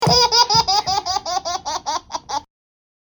cclaugh.mp3